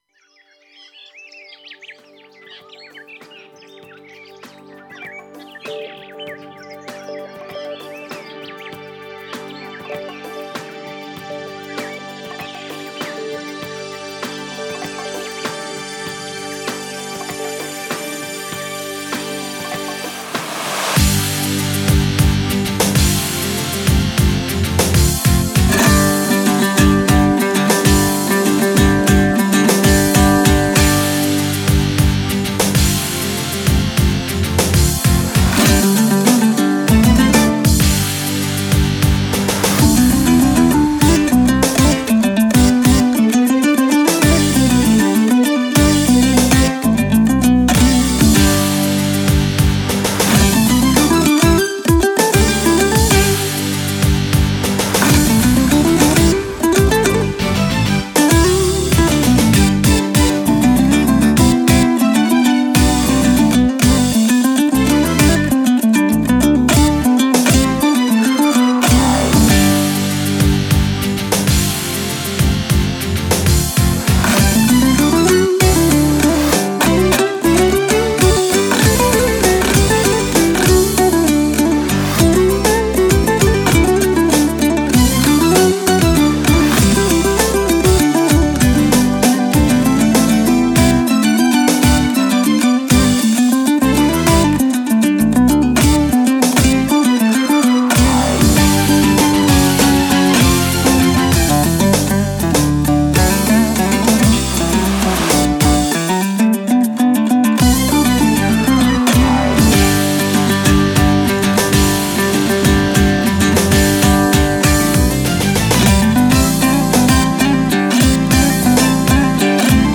это инструментальная композиция в жанре казахского кюя